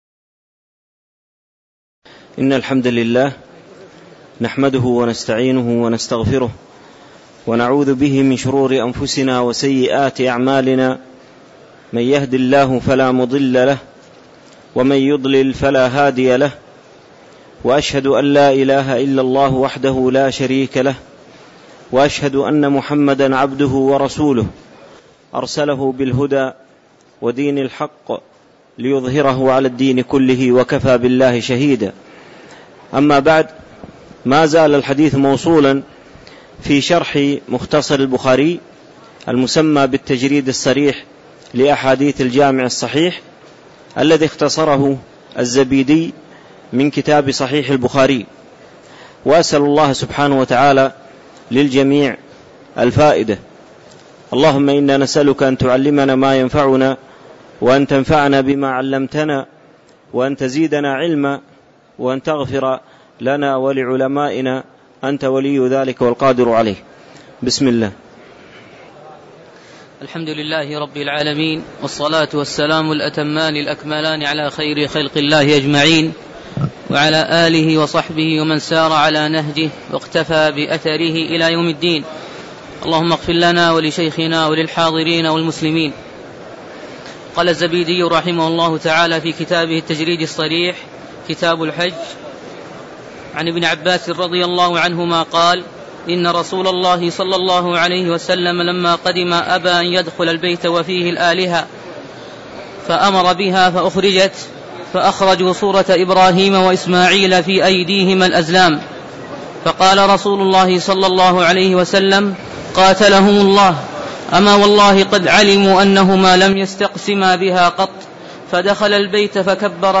تاريخ النشر ١٠ ذو القعدة ١٤٣٧ هـ المكان: المسجد النبوي الشيخ